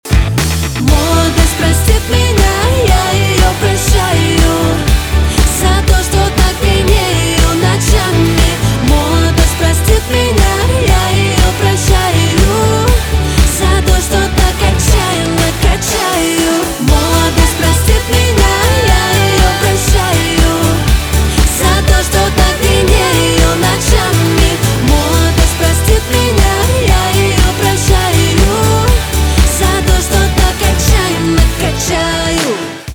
поп
битовые
качающие